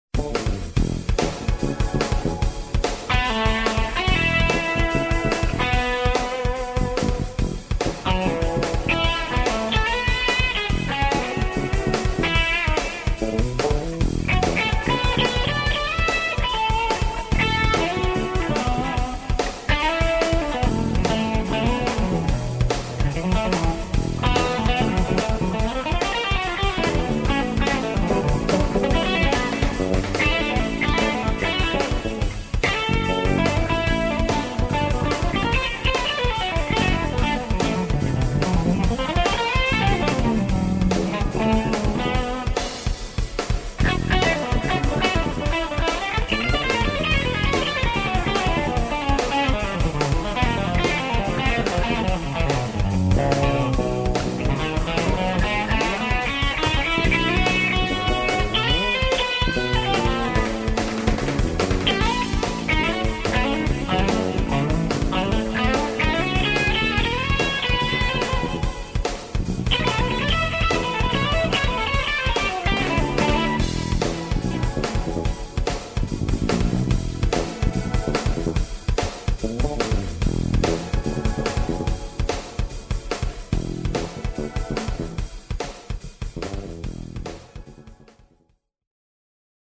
trio jazzowego